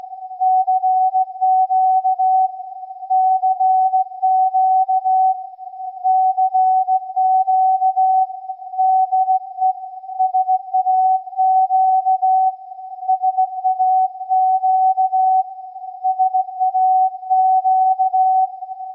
- Rahmenantenne, Ferritantenne, Vorverstärker (selbstgebaut) Laptop mit Panoramic-SAQ 0.94